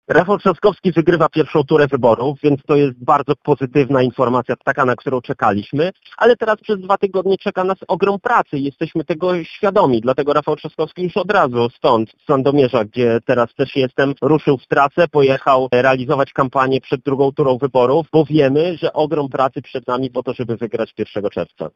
– To bardzo pozytywna informacja, ale mamy świadomość, że przed nami mnóstwo pracy – stwierdził poseł Michał Krawczyk, komentując wyniki I tury wyborów prezydenckich.